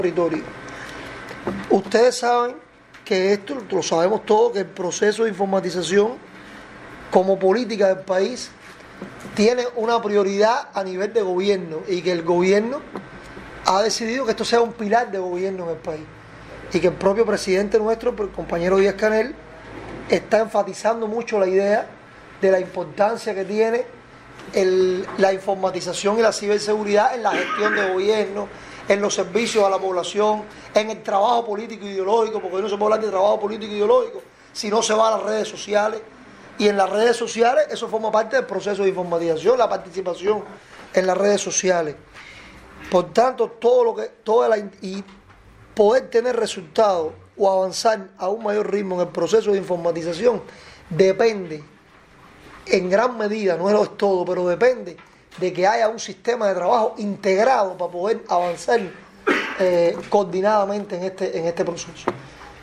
Perdomo Di-Lella sostuvo este 9 de enero, en Bayamo, un encuentro con representantes de entidades locales del Ministerio de Comunicaciones (Mincom), de organismos e instituciones, en el que fue informado sobre su quehacer.
Palabras-Jorge-Luis-Perdomo-Di-Lella.mp3